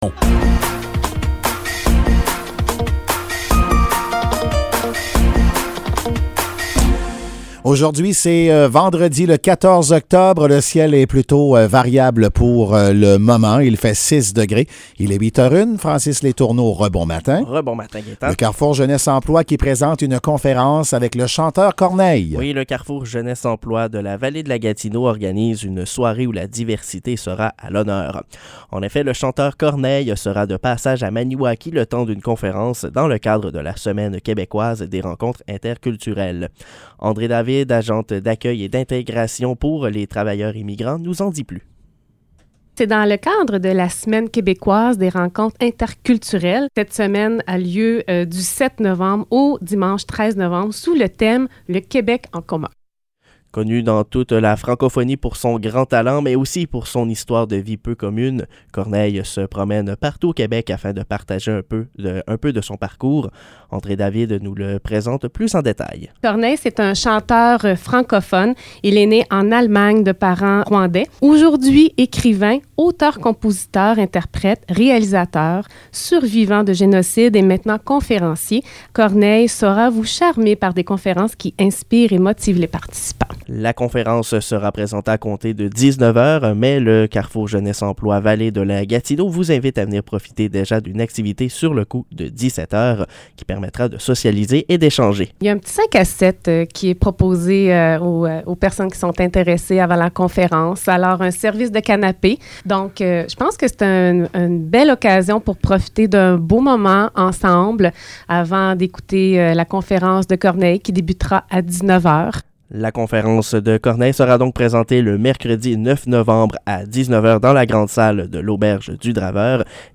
Nouvelles locales - 14 octobre 2022 - 8 h